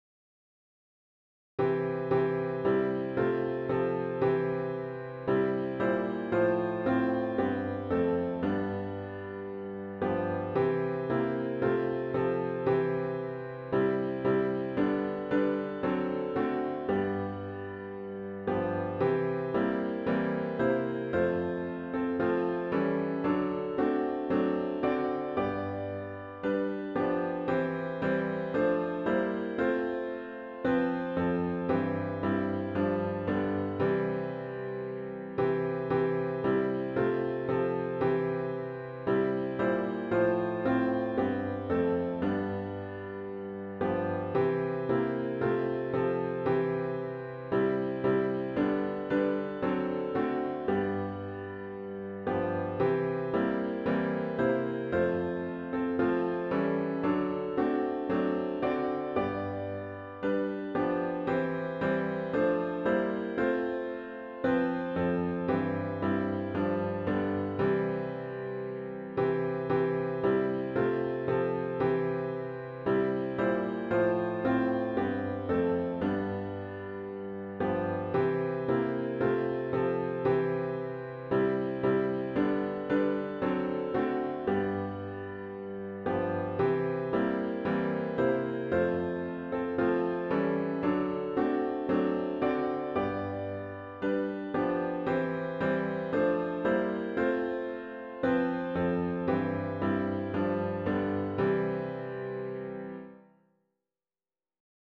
OPENING HYMN   “Lead On, O King Eternal!”